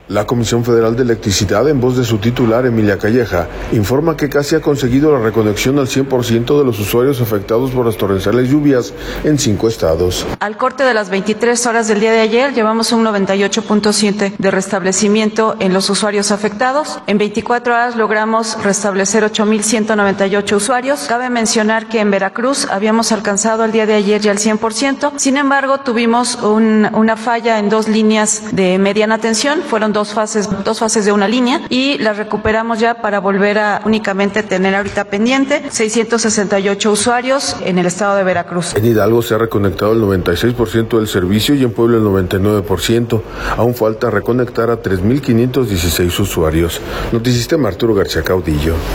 La Comisión Federal de Electricidad en voz de su titular, Emilia Calleja, informa que casi ha conseguido la reconexión al cien por ciento de los usuarios afectados por las torrenciales lluvias en cinco estados.